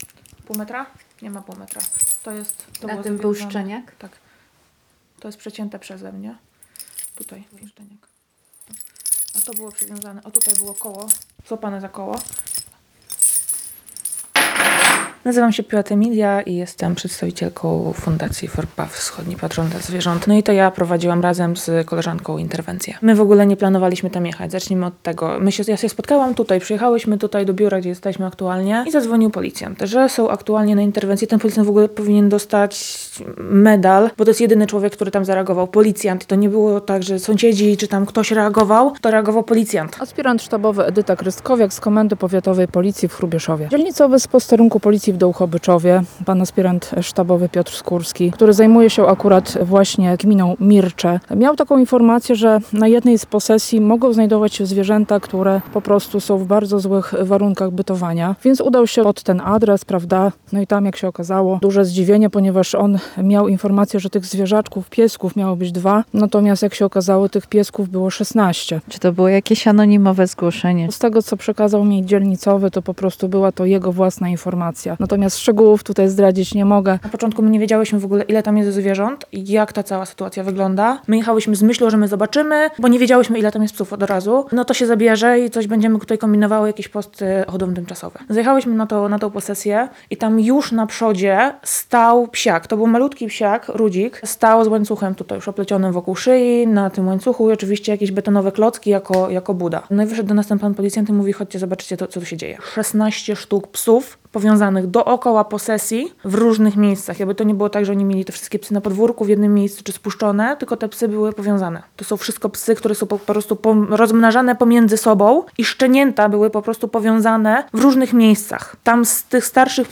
Wraz z nią przedstawicielka z Fundacji 4Paws – Wschodni patrol dla zwierząt.